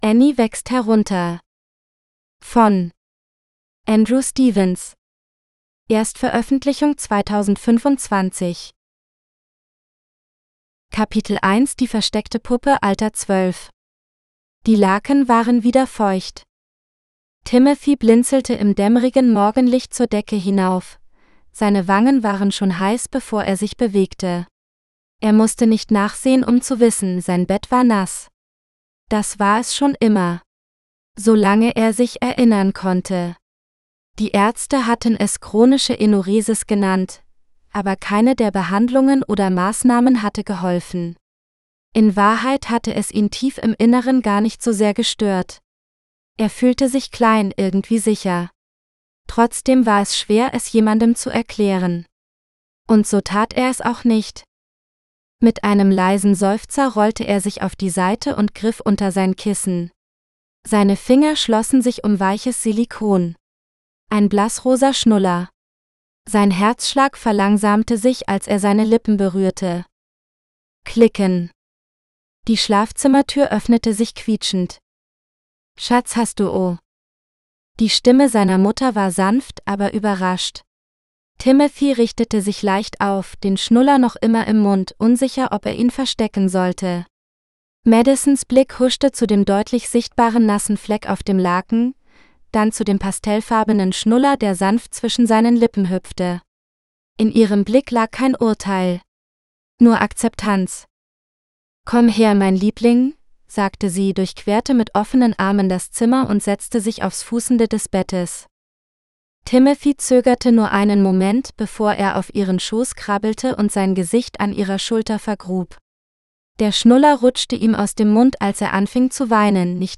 Annie wächst herunter – (AUDIOBOOK – female): $US5.75